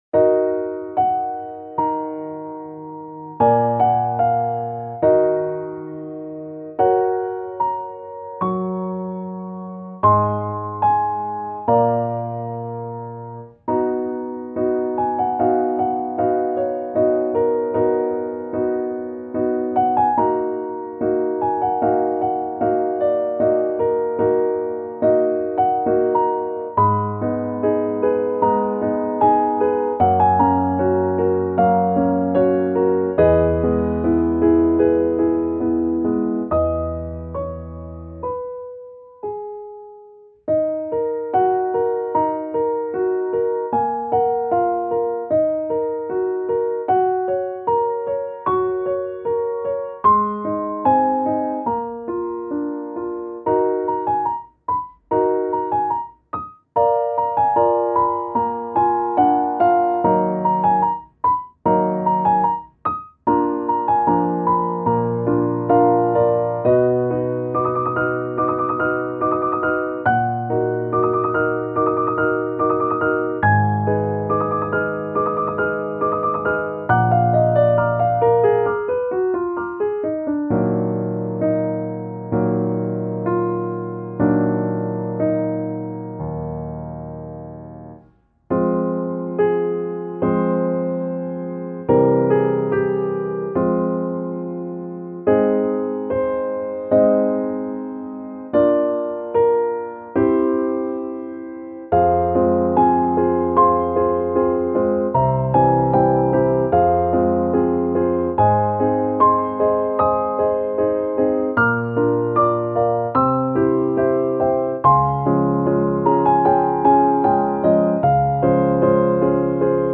イメージ：郷愁 切ない   カテゴリ：ピアノ−暗い・しっとり